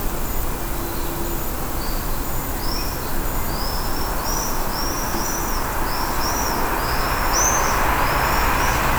Vögel